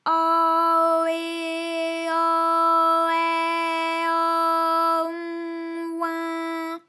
ALYS-DB-001-FRA - First, previously private, UTAU French vocal library of ALYS
au_eh_au_ai_au_on_oin.wav